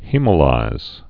(hēmə-līz)